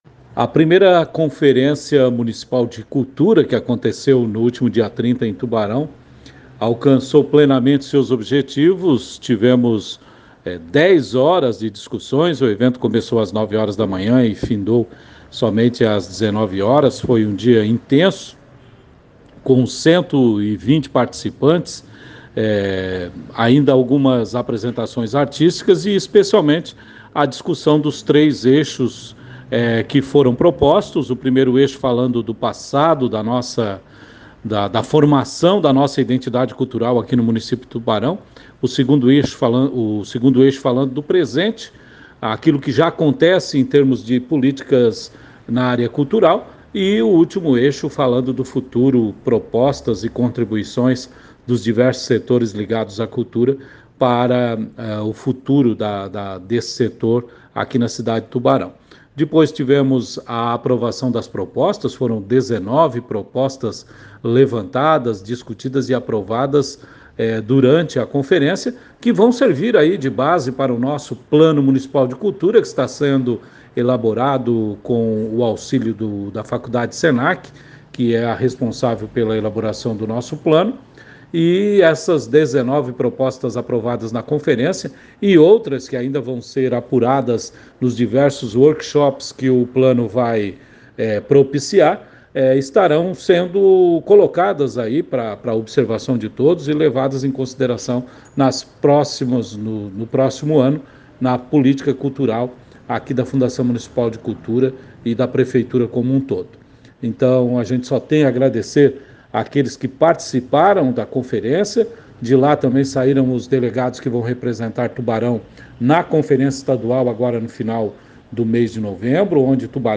01/11/2023 - Diretor-presidente da Fundação Municipal de Cultura Ramires Linhares fala sobre I Conferência Municipal
Diretor-presidente-da-Fundacao-Municipal-de-Cultura-Ramires-Linhares-fala-sobre-I-Conferencia-Municipal.mp3